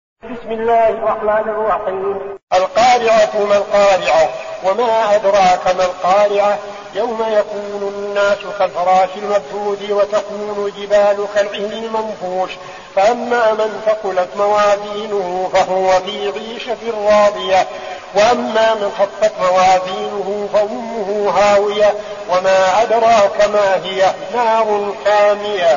المكان: المسجد النبوي الشيخ: فضيلة الشيخ عبدالعزيز بن صالح فضيلة الشيخ عبدالعزيز بن صالح القارعة